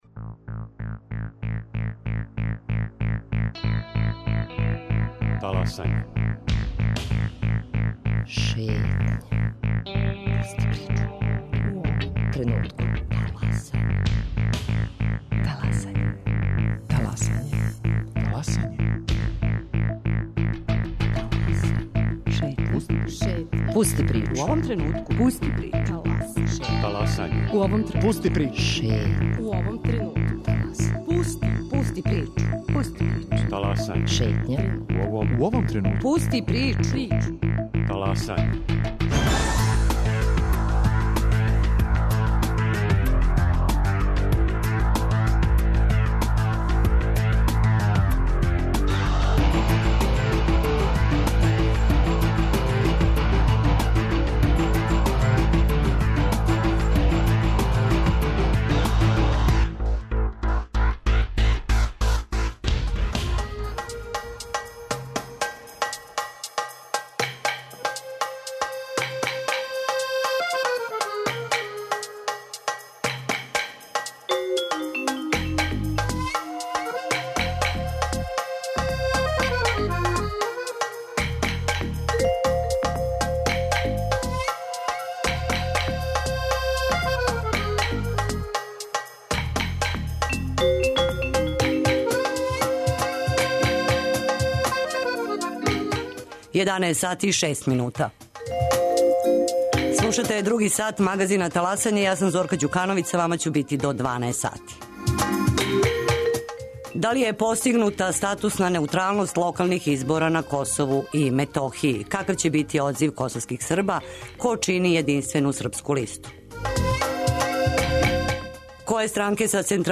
Гости емисије: посланици Народне скупштине Србије, Милован Дрецун и Борко Стефановић